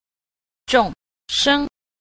7. 眾生 – zhòngshēng – chúng sinh